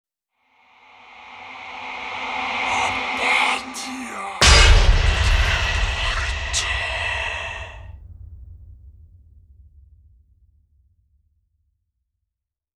Mysterium Whisper reveal